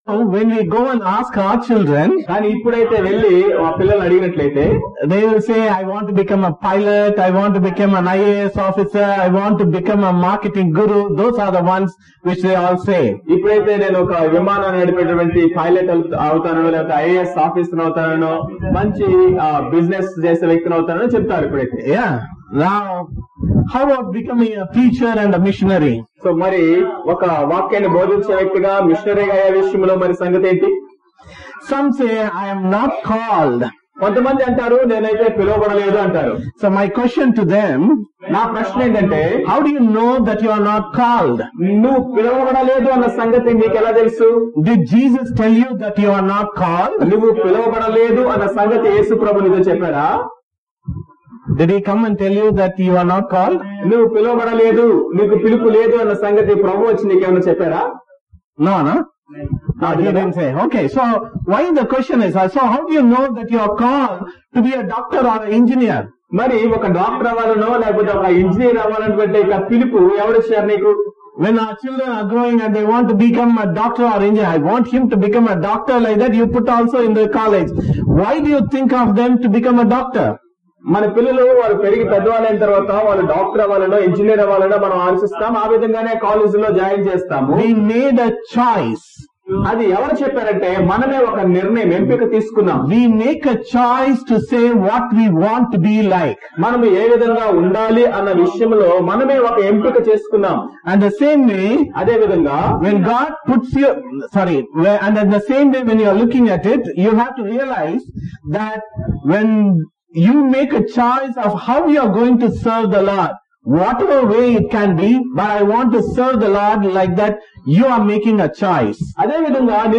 Zion Missionary Conventions 2019 - Day 1 Session 2 - The Call.